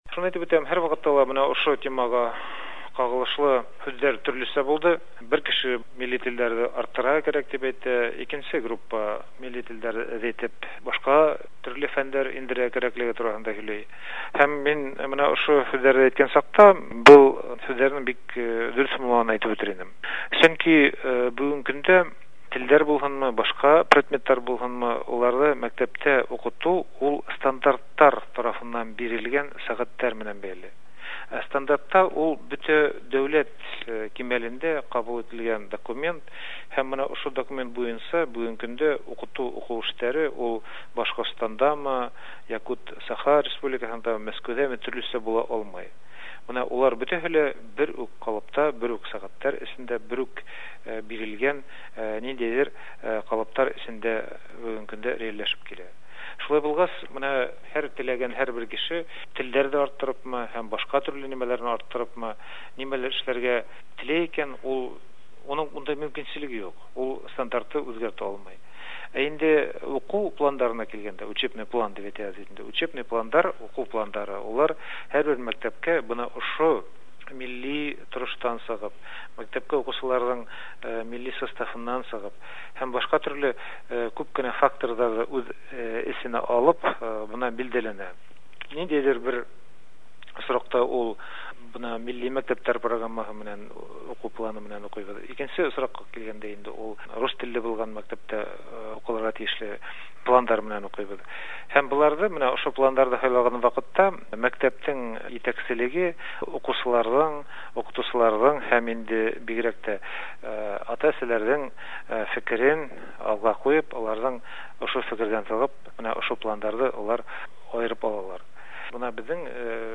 Әлфис Гаязов, Башкорстан мәгариф министры:
Әлфис Гаязов урыс теле хисабына башкорт телен укыту шикаяте турында сөйли